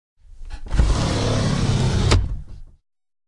衣柜抽屉 " 家用衣柜抽屉 滑动金属开闭软吼动物 004
这是内部抽奖之一的近距离录音。对于这个录音我使用我的Sennheiser MKH416进入声音设备mp1前置放大器并录制到Tascam DR680。
Tag: 滑动 打开 关闭 衣柜 动物 金属 家居 轰鸣 绘制 弗利